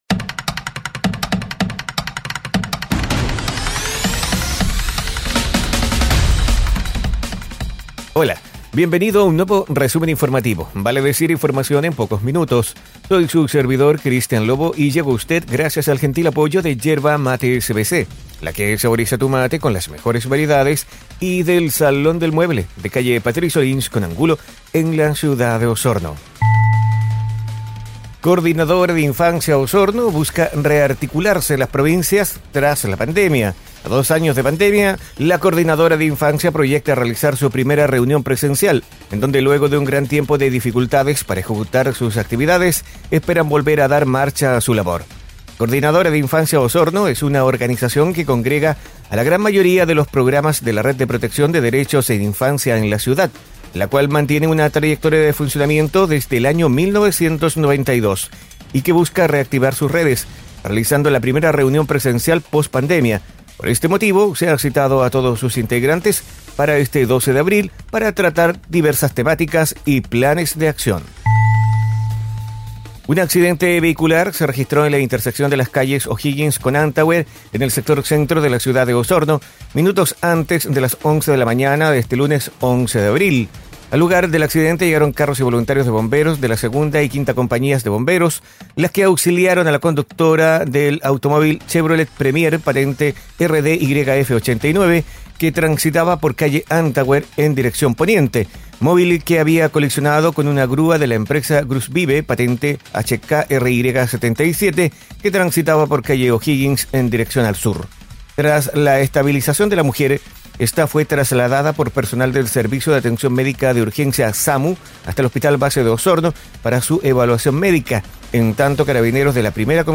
Resumen informativo es un audio podcast con una decena informaciones en pocos minutos